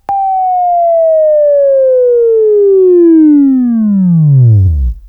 Buzz